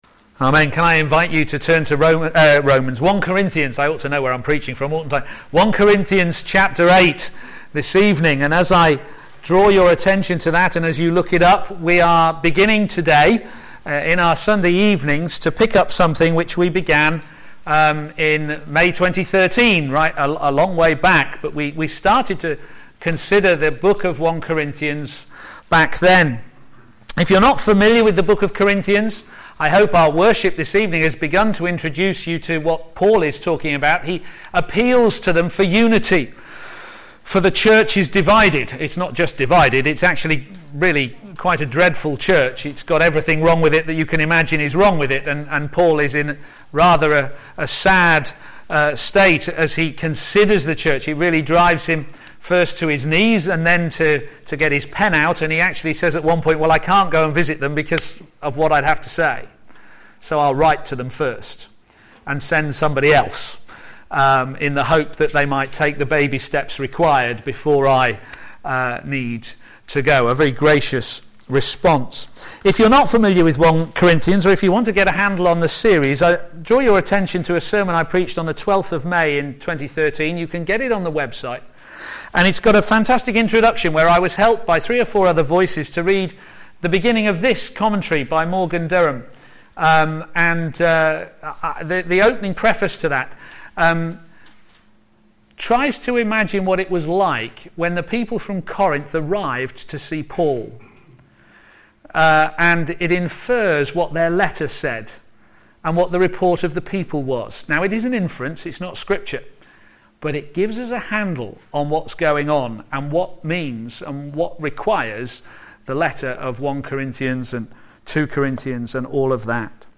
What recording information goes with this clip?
p.m. Service